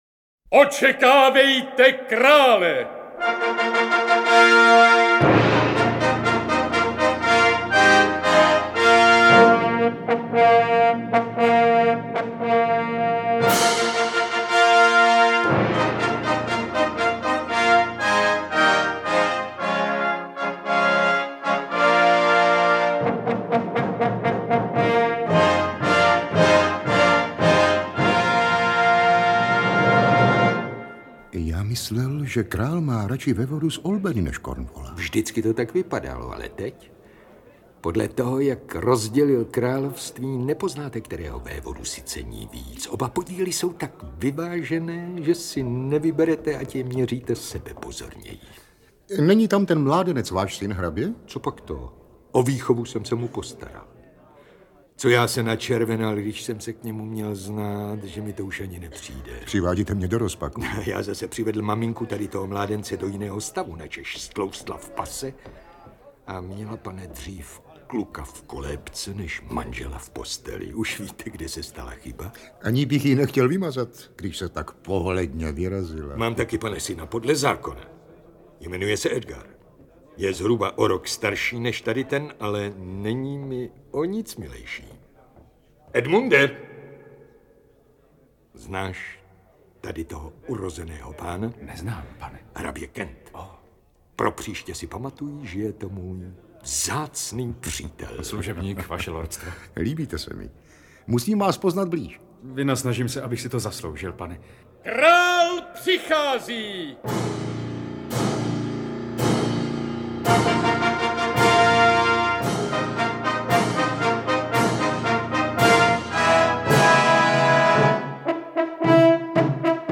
Interpreti:  Jiří Adamíra, Eduard Cupák, Miloš Hlavica, Petr Kostka, Růžena Merunková, Jan Přeučil, Martin Růžek, Pavel Soukup, Alena Vránová